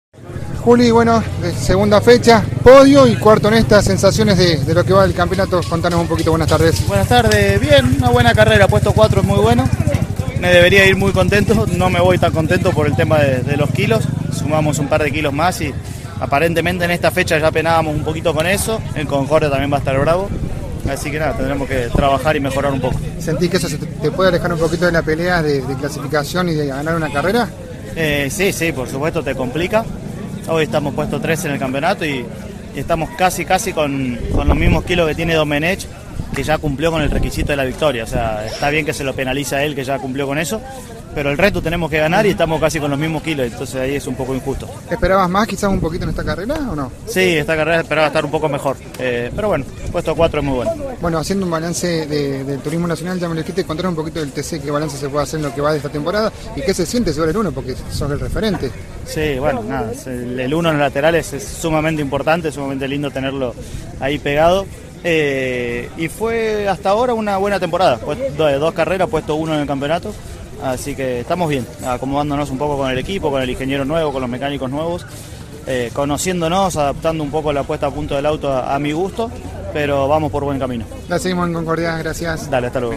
Julián Santero dialogó con CÓRDOBA COMPETICIÓN, luego de la final cordobesa de Clase 3 de Turismo Nacional cordobesa.